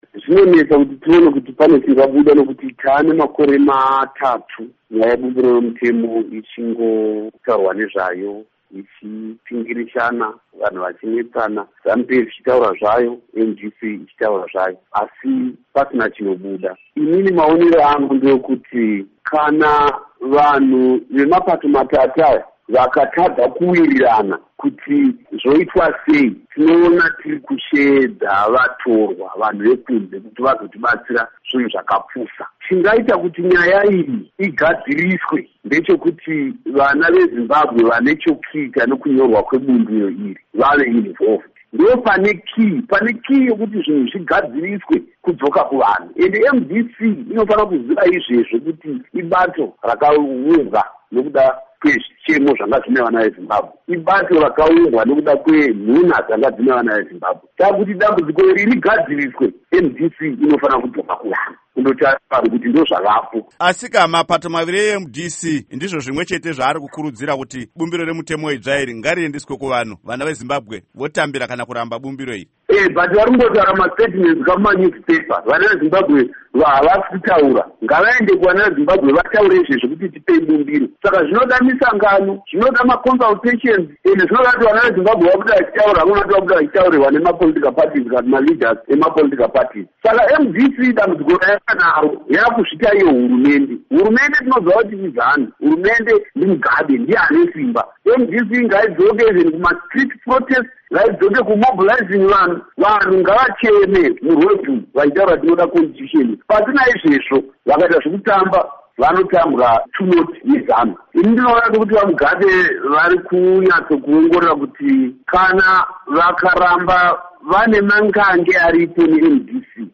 Hurukuro naVaWelshman Ncube